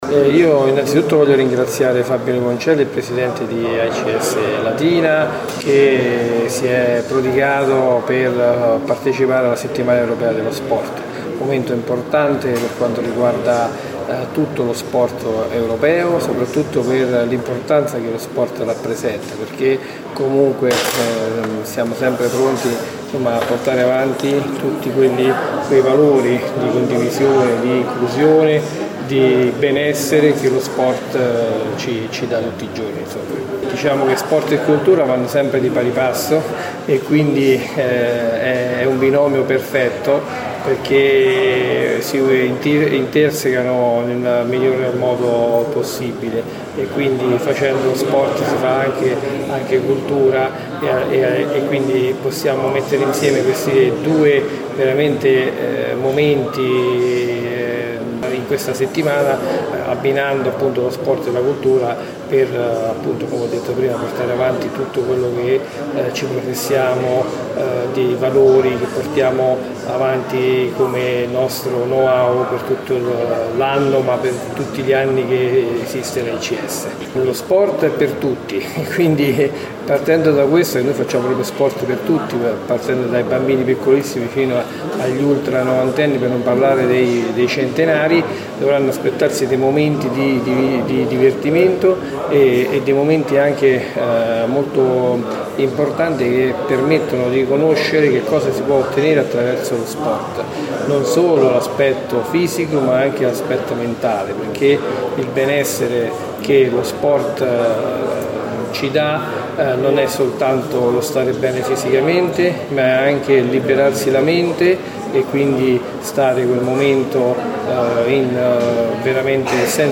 Ieri pomeriggio, presso la sala De Pasquale del Comune, la conferenza stampa di presentazione della prima edizione del Torneo di Pallavolo “Città di Latina”, che prenderà il via oggi con il Villaggio Europeo dello Sport.